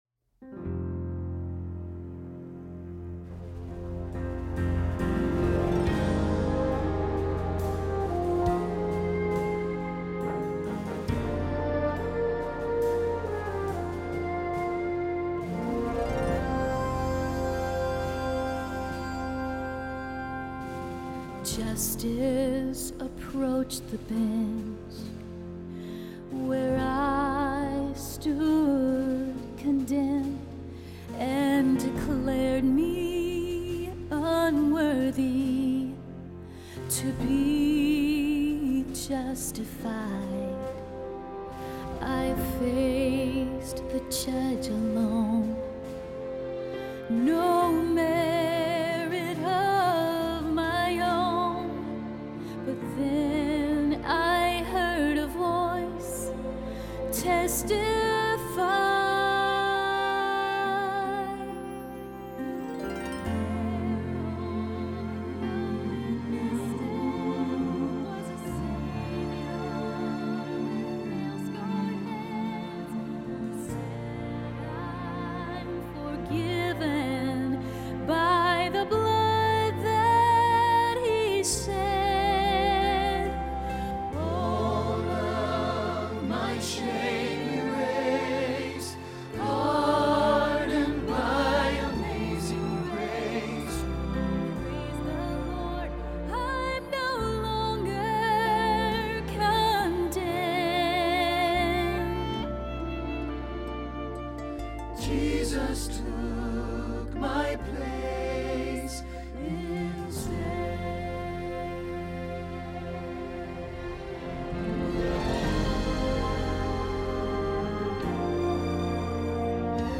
The Witness Stand – Tenor – Hilltop Choir
01-The-Witness-Stand-tenor.mp3